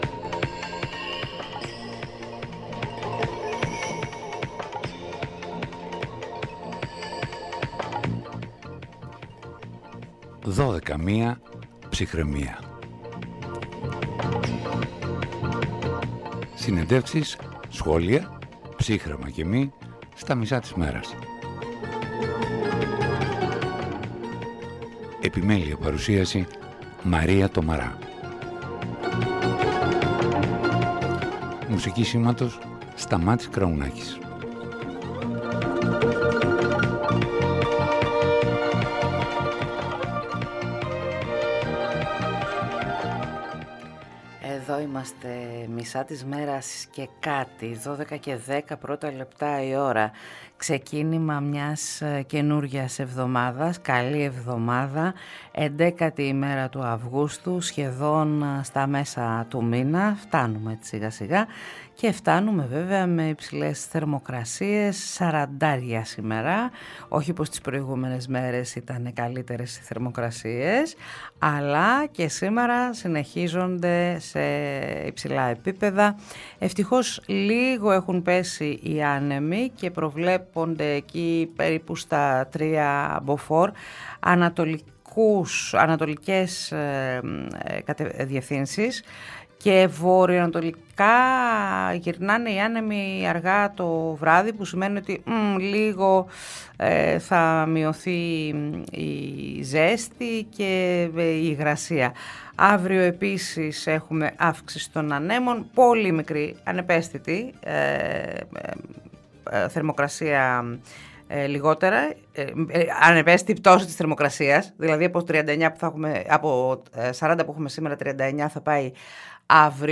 H Γιούλικα Σκαφιδά στο ραδιόφωνο της ΕΡΤ Καλαμάτας | 11.08.2025